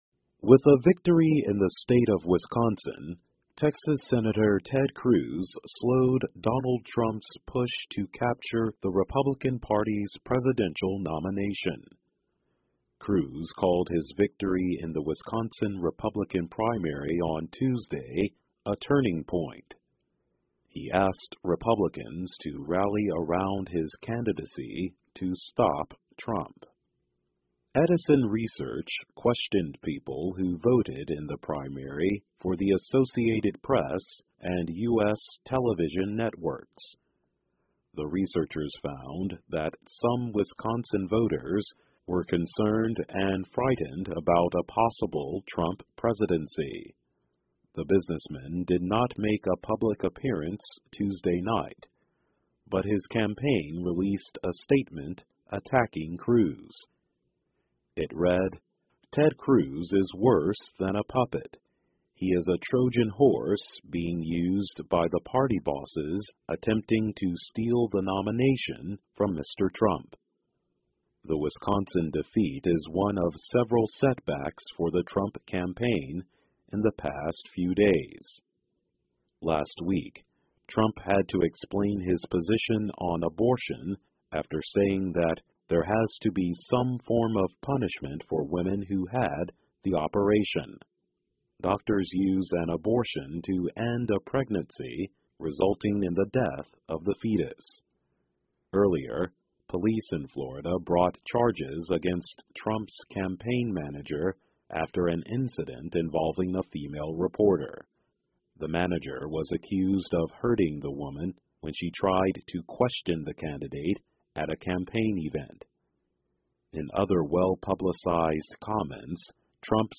在线英语听力室克鲁兹胜利使特朗普受阻的听力文件下载,2016年慢速英语(四)月-在线英语听力室